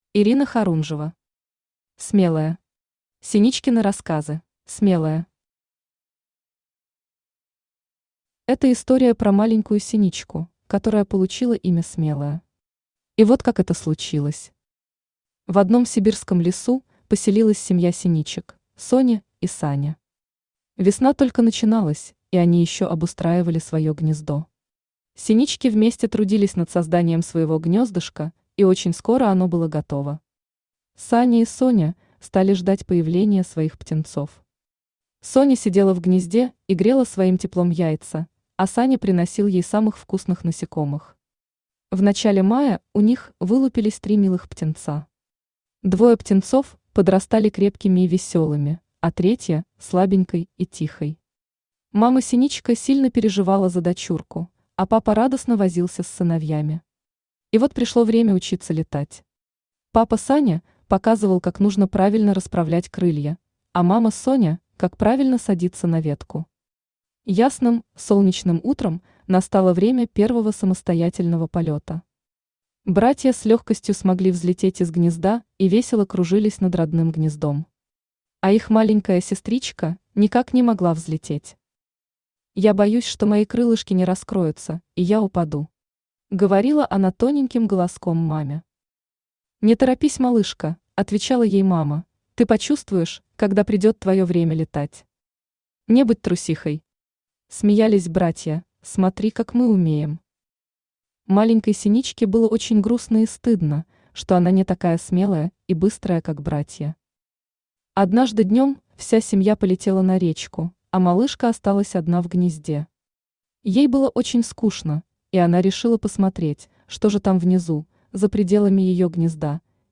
Синичкины рассказы Автор Ирина Хорунжева Читает аудиокнигу Авточтец ЛитРес.